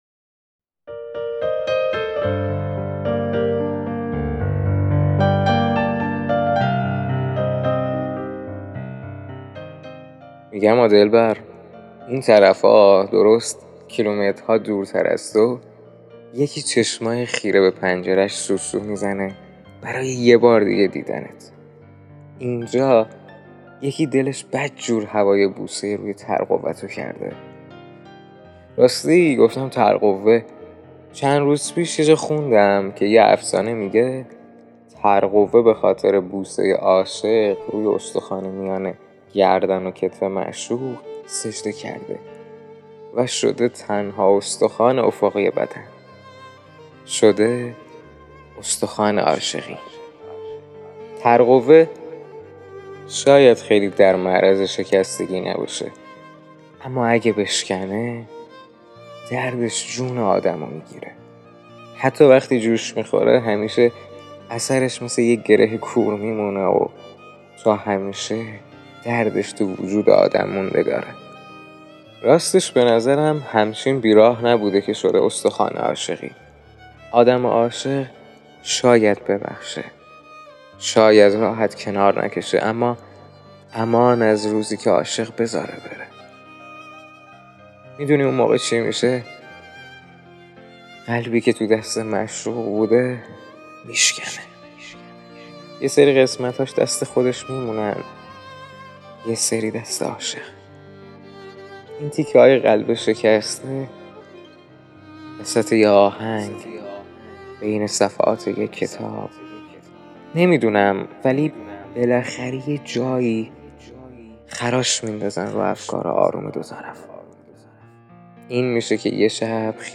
تک اهنگ